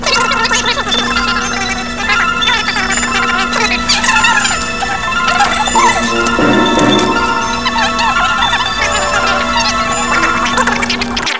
This sound sample or audio image comprises 45.62 secs of digitised speech, spoken by humans.